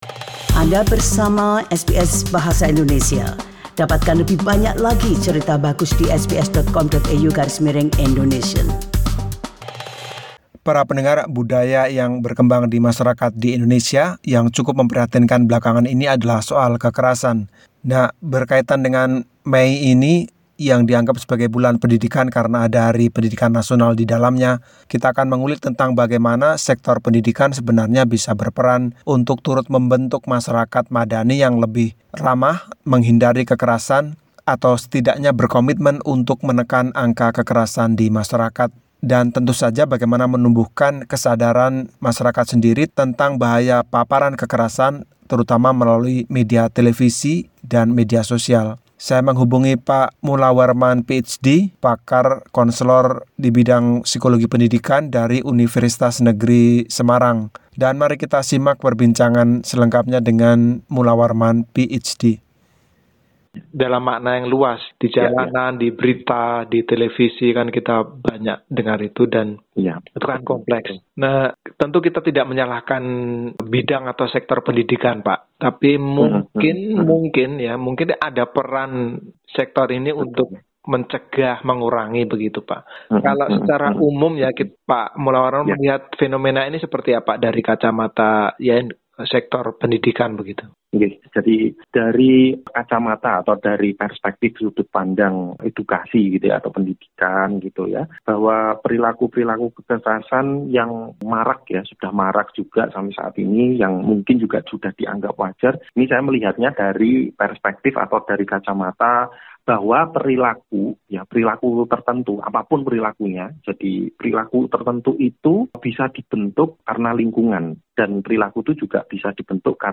Dalam wawancara ini dia menawarkan sejumlah jalan keluar, tentang bagaimana membentuk budaya nir kekerasan di tengah masyarakat, dimulau dari anak-anak.